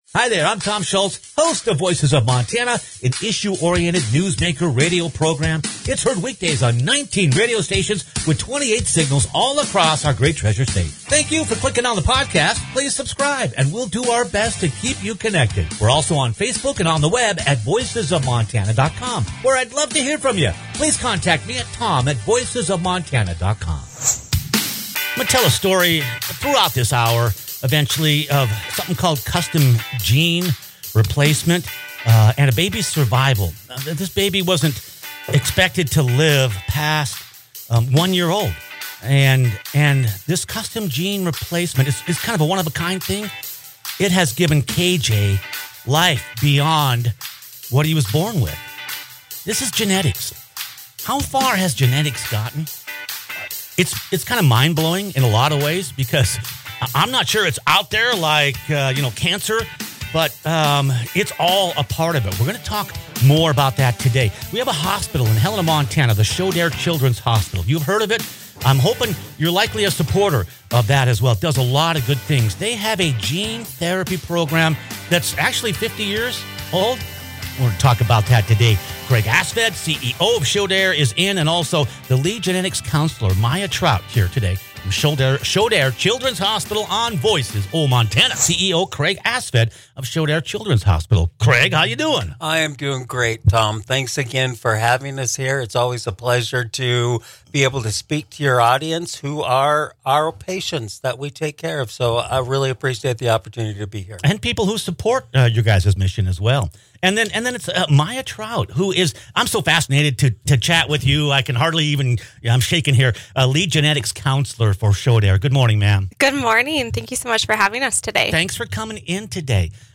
Special guests in-studio for